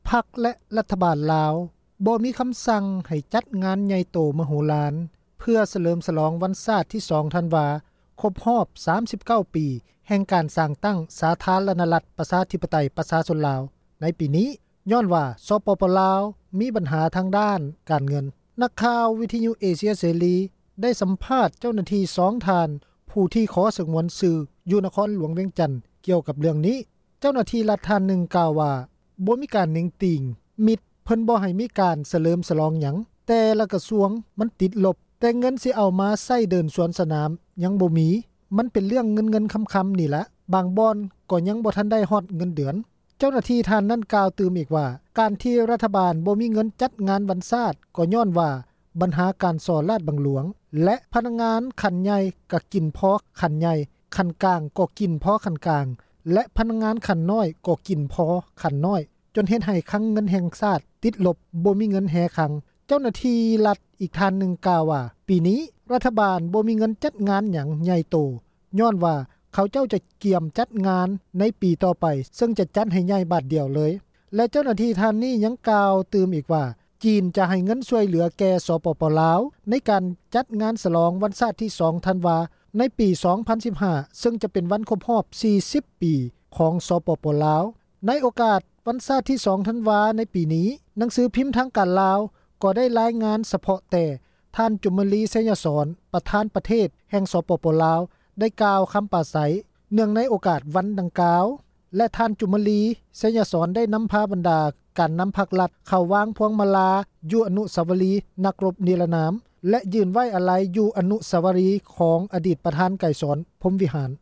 ພັກ ແລະ ຣັຖບານ ລາວ ບໍ່ມີຄຳສັ່ງ ຈັດງານ ໃຫຍ່ໂຕ ມະໂຫລານ ເພື່ອ ສເລີມສລອງ ວັນຊາດ ທີ 2 ທັນວາ ຄົບຮອບ 39 ແຫ່ງ ການ ສ້າງຕັ້ງ ສາທາຣະນະຣັດ ປະຊາທິປະໄຕ ປະຊາຊົນ ລາວ ໃນ ປີນີ້ ຍ້ອນວ່າ ສປປ ລາວ ມີບັນຫາ ທາງ ດ້ານ ການເງິນ. ນັກຂ່າວ ວິທຍຸ ເອເຊັຽ ເສຣີ ໄດ້ສຳພາດ ເຈົ້າໜ້າທີ່ ຂອງ ຣັຖ 2 ທ່ານ ຜູ້ທີ່ ຂໍສງວນ ຊື່ ຢູ່ ນະຄອນ ຫລວງ ວຽງຈັນ ກ່ຽວກັບ ເລື້ອງນີ້.